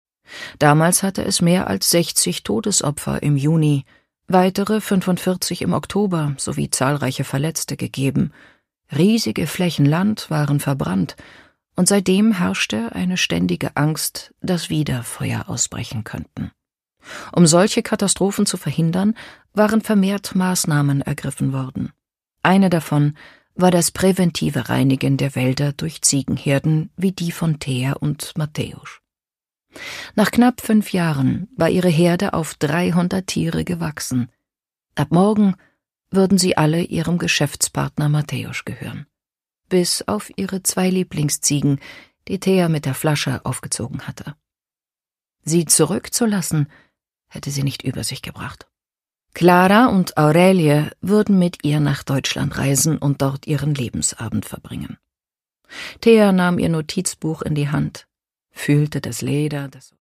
Produkttyp: Hörbuch-Download
Gelesen von: Tessa Mittelstaedt
Als Hörbuchsprecherin weiß sie Temperamente und Stimmungen von nordisch-kühl bis herzlich gekonnt auszudrücken.